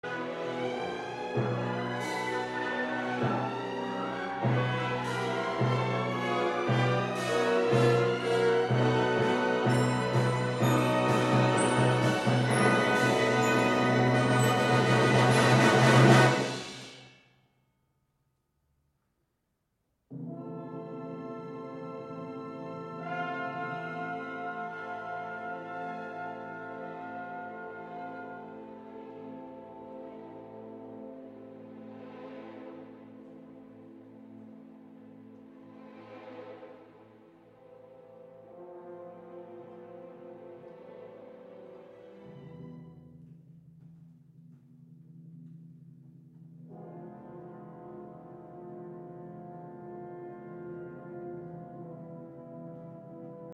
Lots of dissonances can be heard, presumably as he fades always in agony. The end chord is in e-minor (Minor-tonic).
In terms of instrumentation Strauss chose, as it was usual for the Romantic period, a big orchestra, the instruments were the following: 3 flutes, English horn, 2 clarinets, 2 bassoons, contrabassoon, 4 horns, 3 trumpets, 3 trombones tuba, harp, timpani, triangle, cymbal, chime and strings. 10